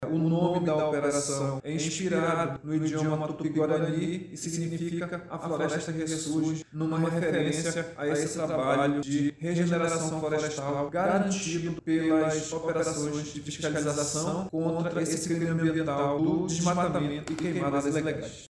SONORA-2-Joel-Araujo.mp3